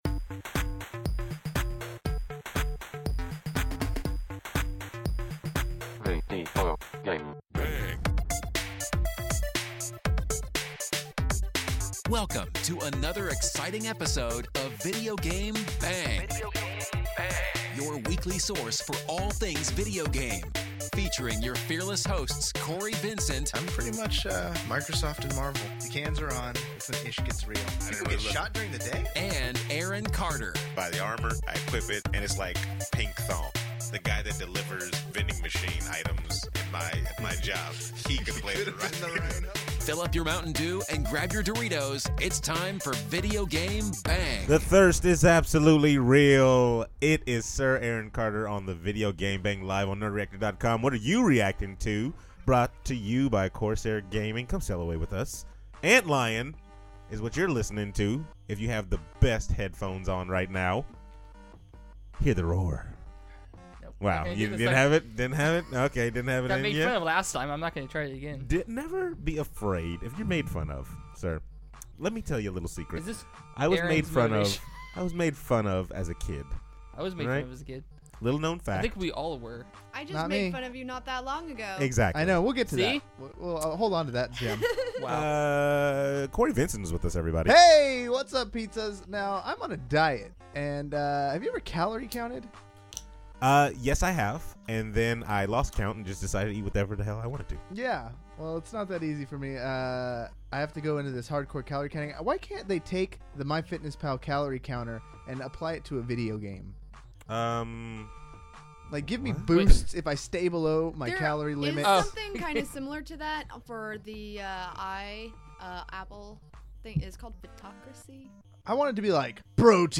This week the crew talks about the new Suicide Squad poster, why Deadpool should stay rated R, Atari's new Steam vault collection, chocolate french fries, the first gamer hotel, poop slinging in ARK and much more. Make sure to subscribe and tune in every Saturday night at 9pm on Twitch for the live recording of the show.